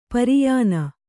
♪ pariyāna